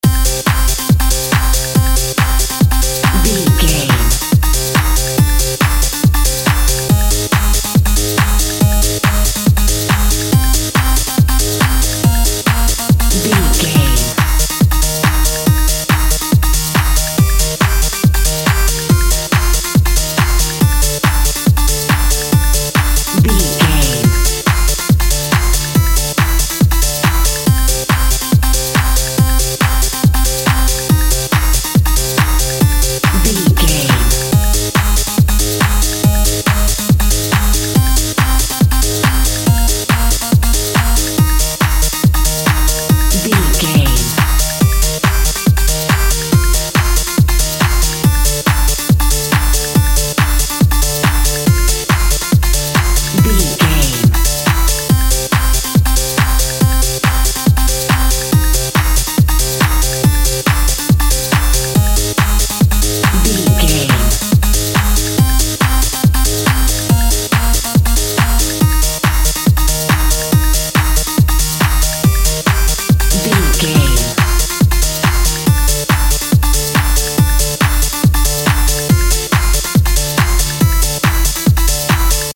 Advert Techno Music.
Aeolian/Minor
hypnotic
industrial
driving
energetic
frantic
drum machine
synth lead
synth bass